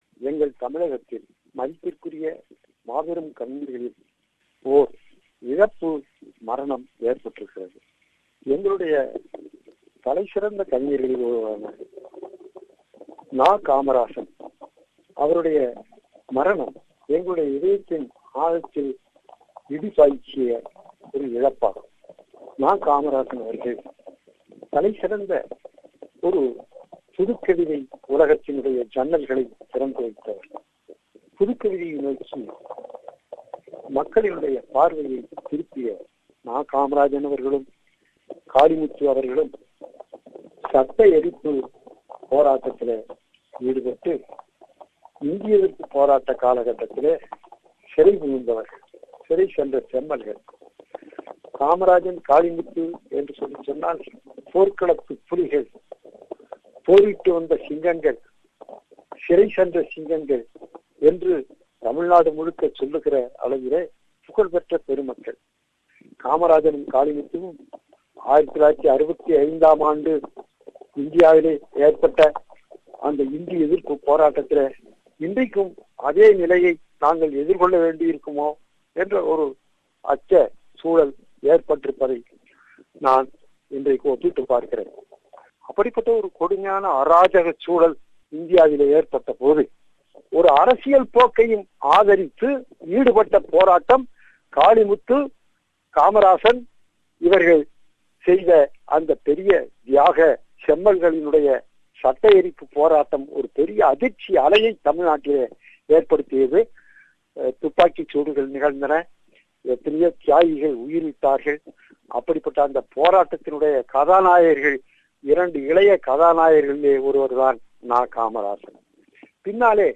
A eulogy for Poet N.Kamarasan
A eulogy for poet N.Kamarasan by contemporary poet and Kamarasans friend Mu.Meththa.